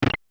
button.wav